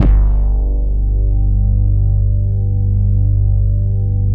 23 BASS   -L.wav